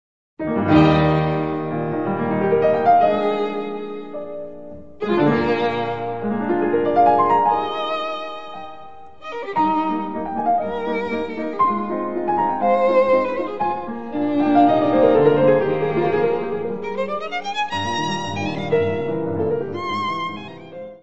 : stereo; 12 cm + folheto
violino
Music Category/Genre:  Classical Music
Sonatas for violin and piano